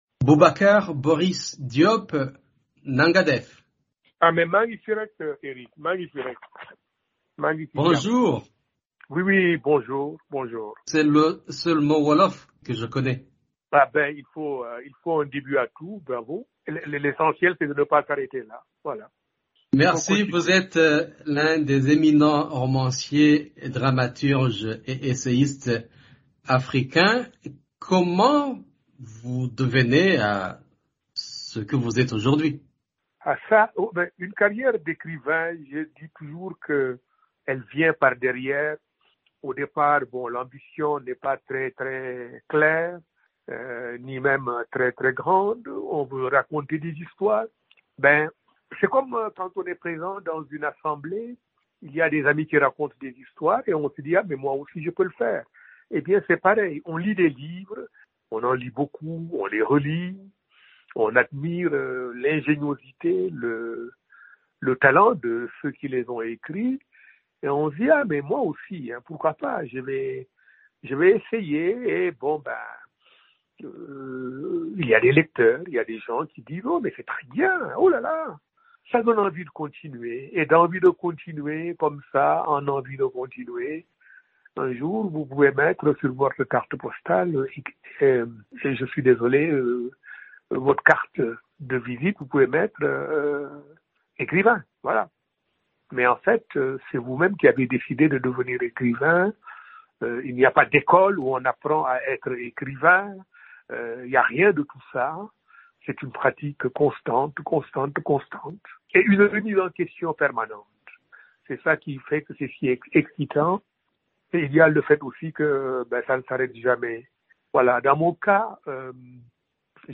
Dans un entretien exclusif à VOA Afrique, Boris raconte ce que le prix qu’il a reçu, équivalent du Nobel de littérature, représente pour lui.